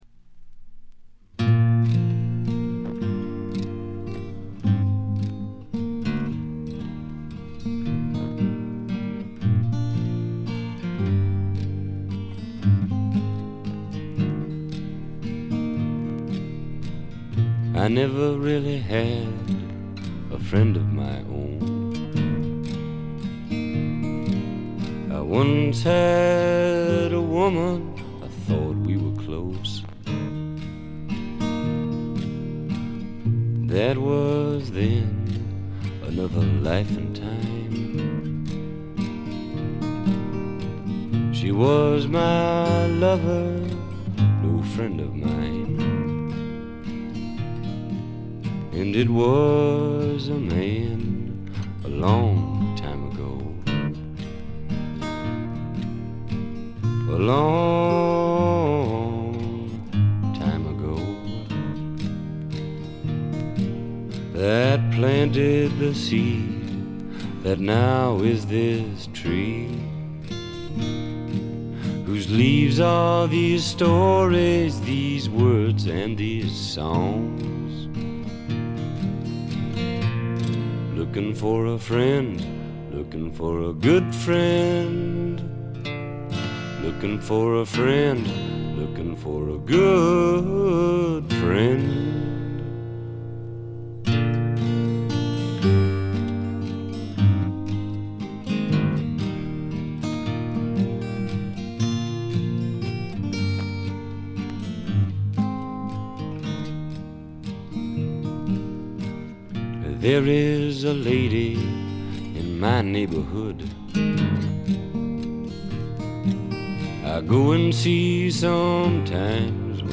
これ以外はわずかなチリプチ程度。
試聴曲は現品からの取り込み音源です。